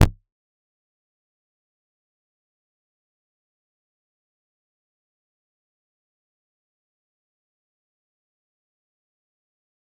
G_Kalimba-F0-f.wav